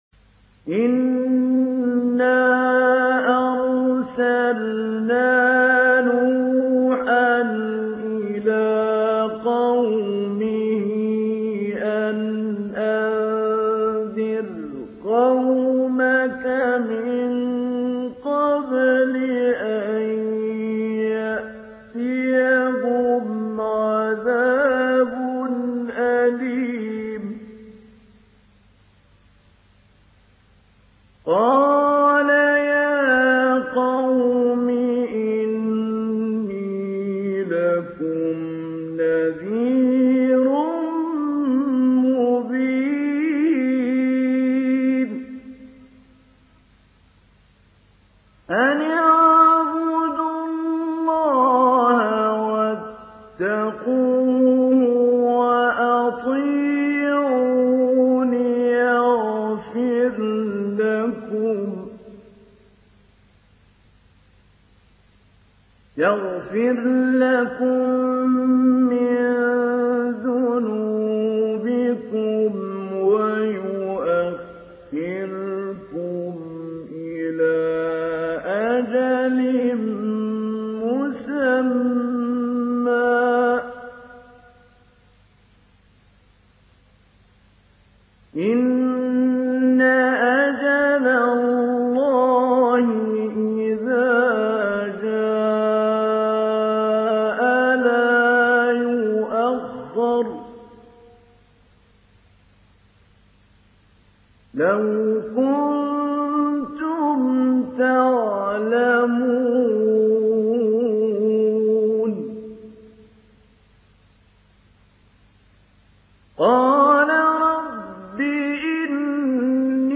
Surah নূহ MP3 by Mahmoud Ali Albanna Mujawwad in Hafs An Asim narration.